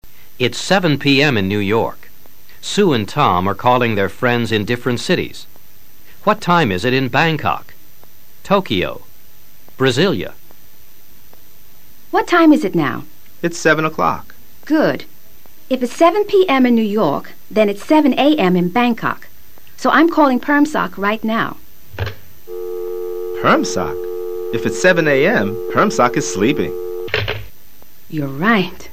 Esta es una entretenida sesión de audio comprensión dividida en TRES partes.